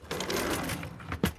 Cutlery Drawer Close Sound
household
Cutlery Drawer Close